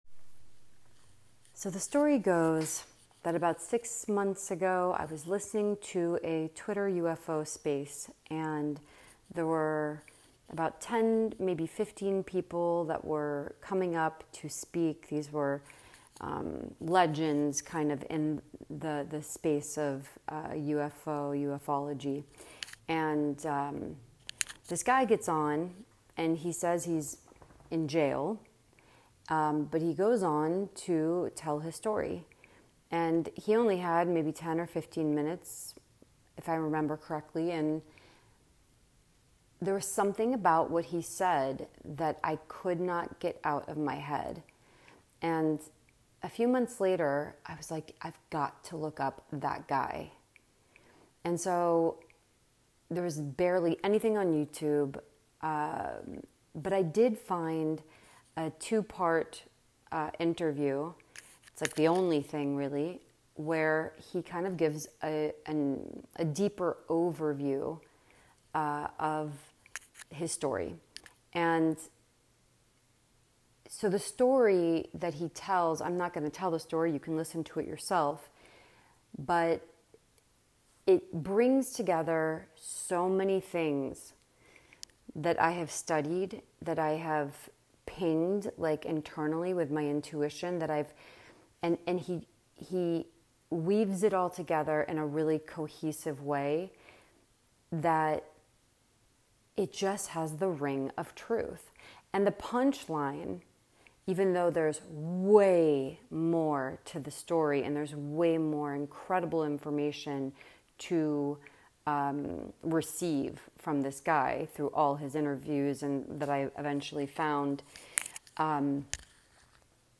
💫💫 Click here to start by listening to this voice note 💫💫
🛸 Step 1) 👇👇👇👇👇👇👇👇👇👇👇👇👇👇 💫💫 Click here to start by listening to this voice note 💫💫 👆👆👆👆👆👆👆👆👆👆👆👆👆👆 This is me introducing you to what this is about, how I came to the information and why I think it's important. Please pardon the poor audio quality.